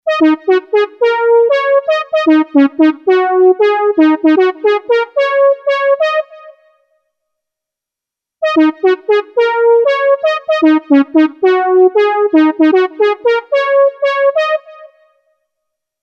Patch 100 LEAD
- Brassy Ld 1
TOP8_BrassyLd1.mp3